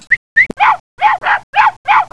A stupid little dog barking
bark.wav